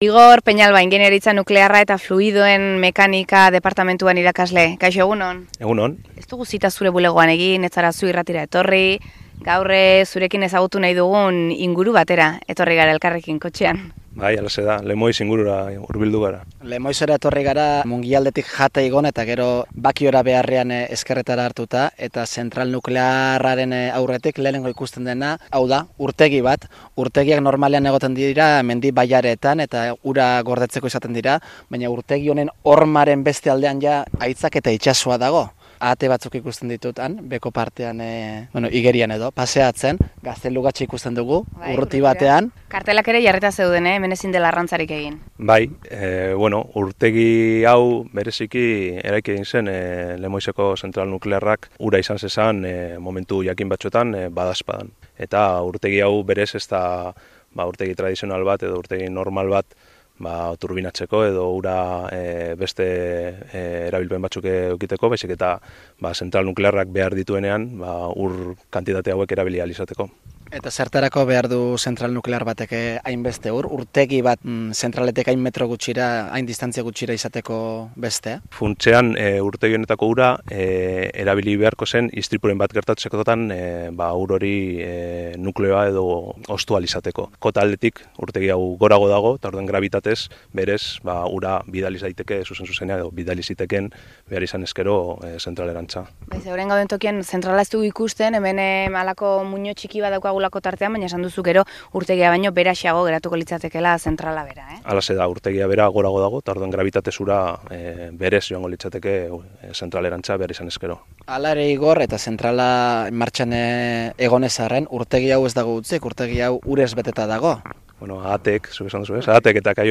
Audioa: Lemoizko zentral nuklearrean izan gara